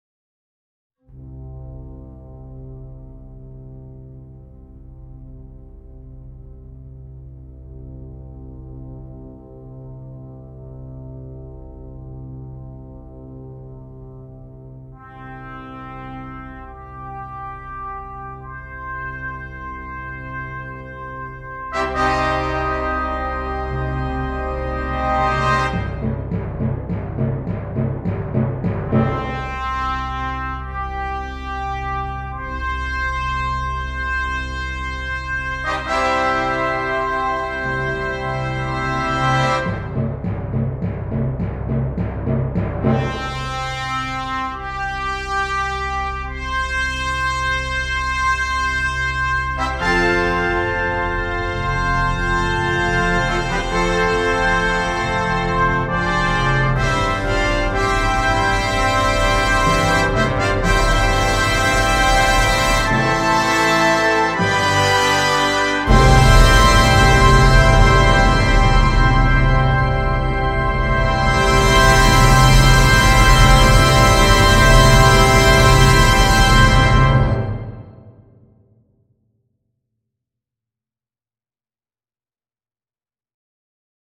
Brass Choir (4.4.3.2.1.perc.organ)
Difficulty: Medium-Difficult Order Code